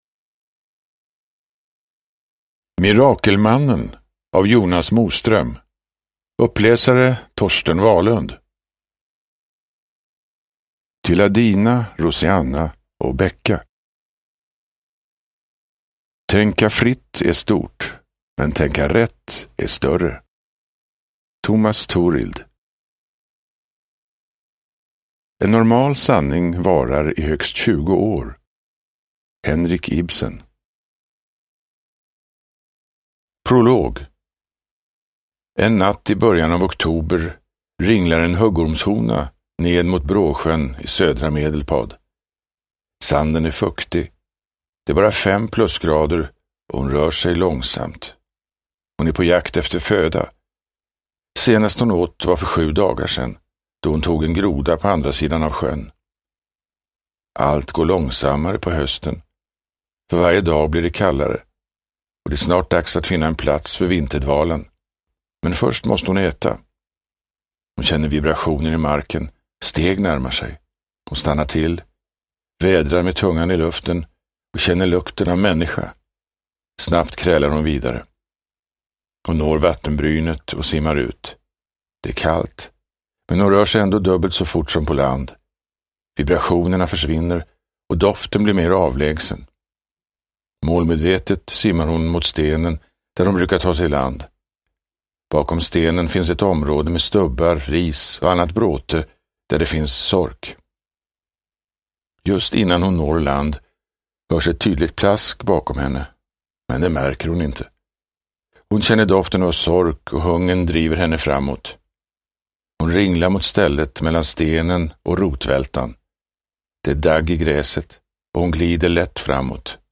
Mirakelmannen – Ljudbok – Laddas ner
Uppläsare: Torsten Wahlund